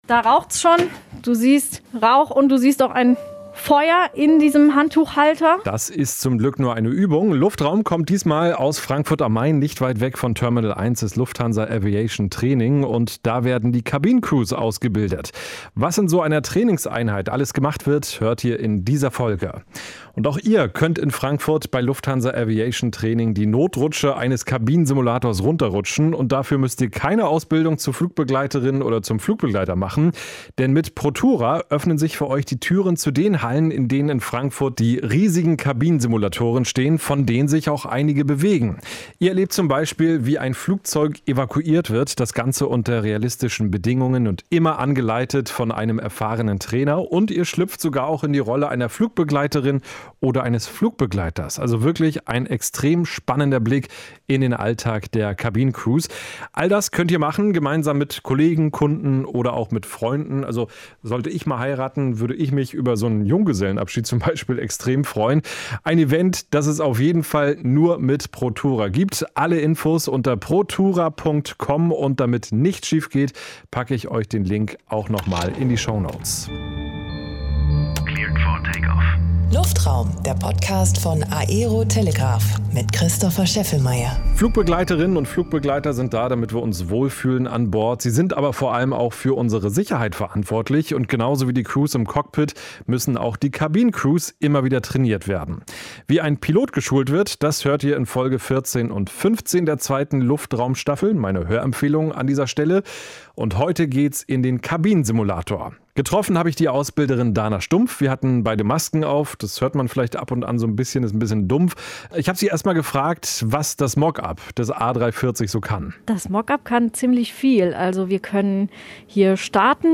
Beschreibung vor 3 Jahren In einer riesigen Halle stehen am Frankfurter Flughafen mehrere Kabinensimulatoren, unter anderem vom A380 oder der Boeing 747.